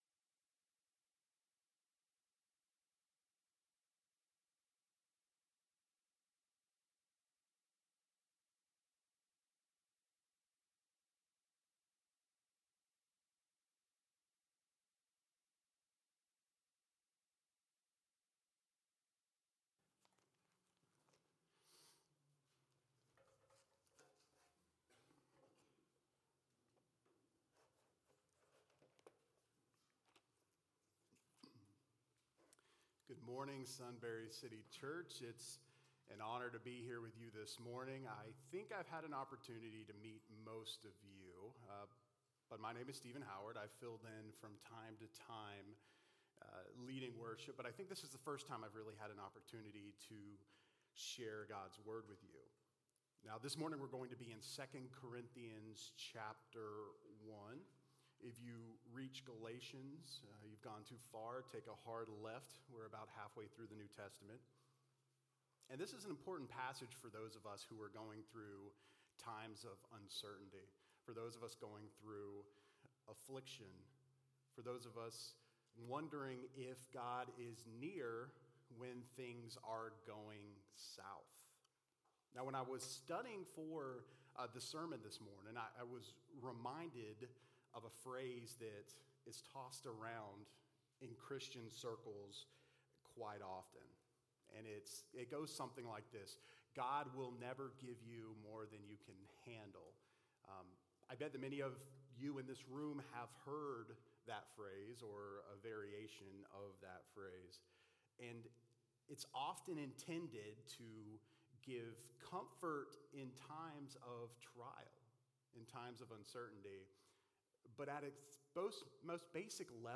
Do you find yourself wondering if God is near when things go south? In this sermon we're reminded of where God is during our suffering.